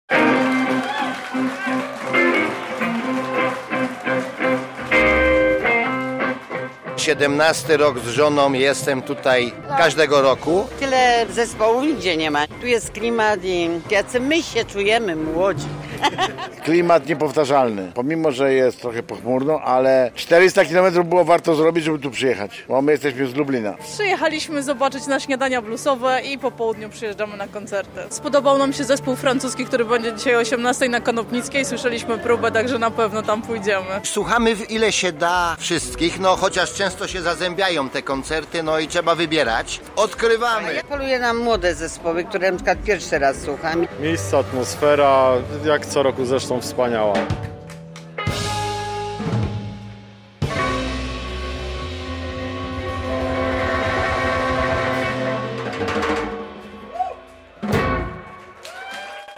Zanim zagrają na dużej scenie - bluesowe śniadania w Suwałkach - relacja